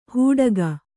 ♪ hūḍa